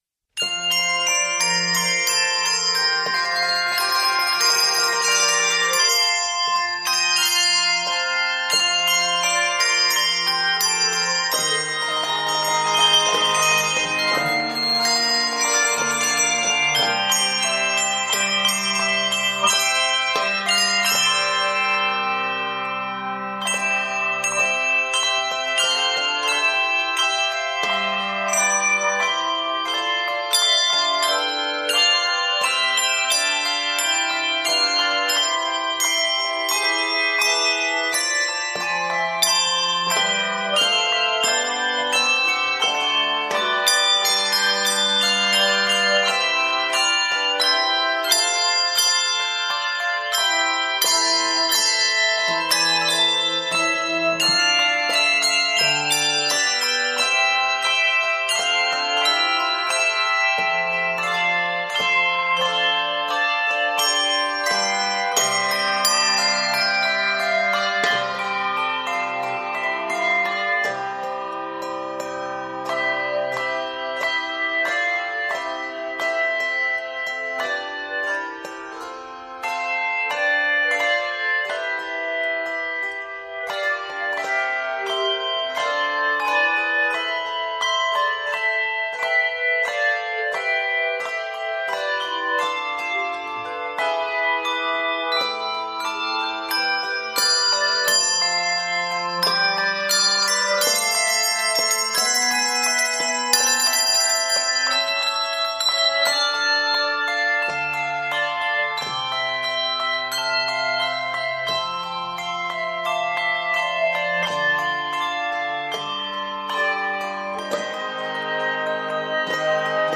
Inspire a festive mood